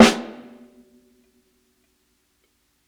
60s_SNARE_MED.wav